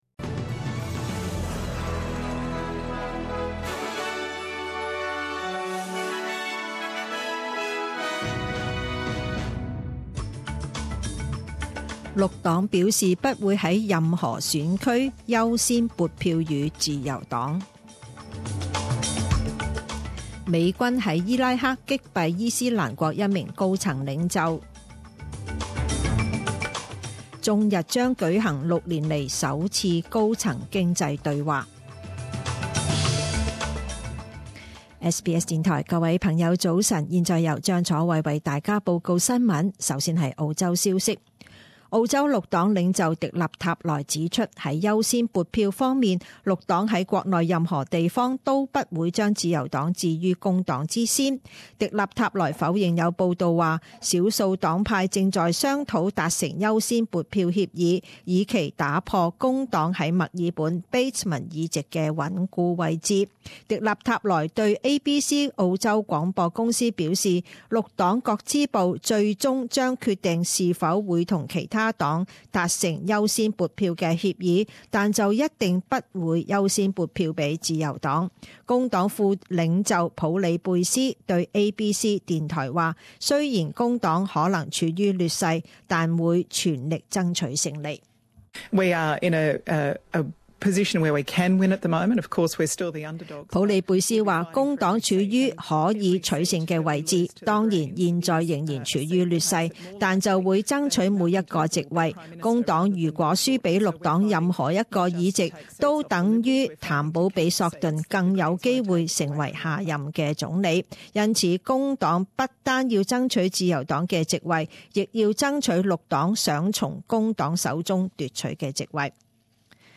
五月十日十点钟新闻报导